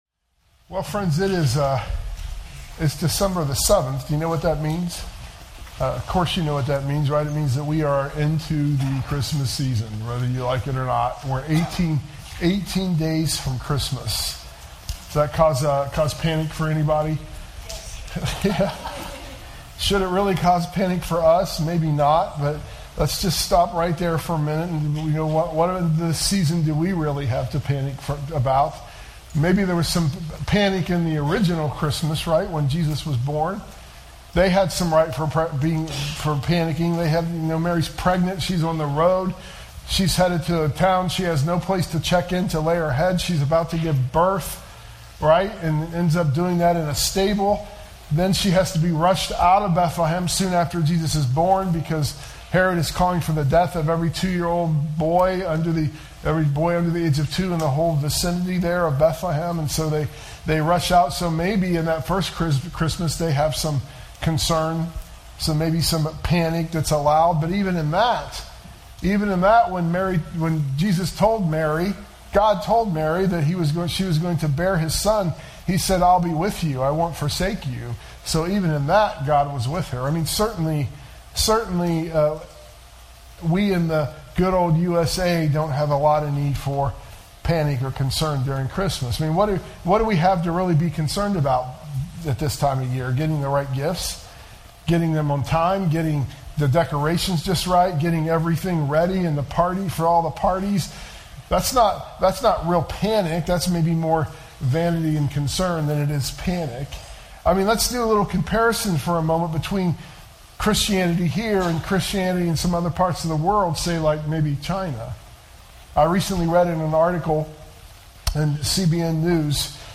sermon_audio_mixdown_12_7_25.mp3